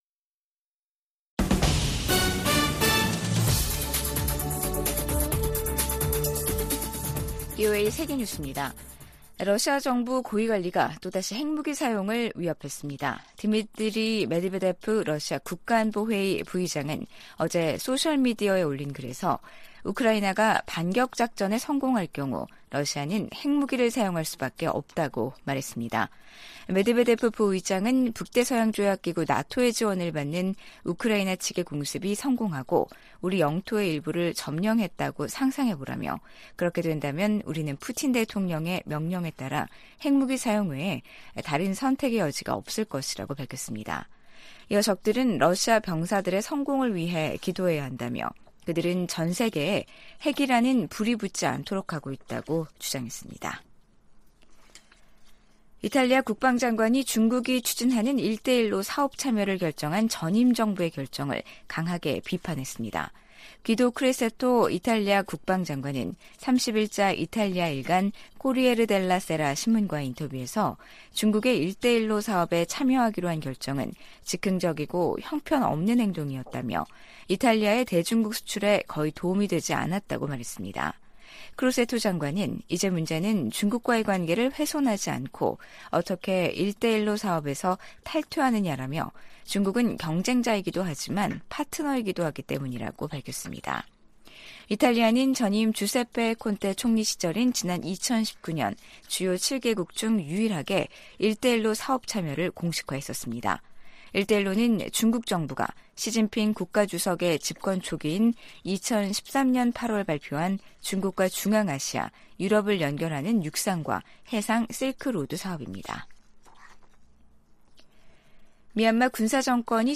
VOA 한국어 간판 뉴스 프로그램 '뉴스 투데이', 2023년 7월 31일 3부 방송입니다. 백악관이 미한일 3국 정상회담 개최를 공식 발표하며 북한 위협 대응 등 협력 확대 방안을 논의할 것이라고 밝혔습니다. 미 국무부는 줄리 터너 북한인권특사 지명자에 대한 상원 인준을 환영했습니다. 미 상원이 2024회계연도 국방수권법안을 통과시켰습니다.